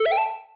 spin.ogg